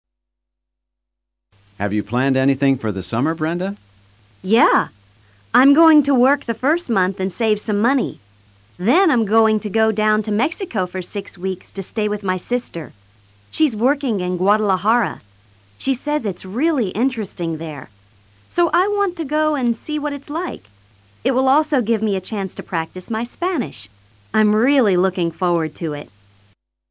Esta sección, dividida en tres diálogos, presenta diferentes formas de vacacionar.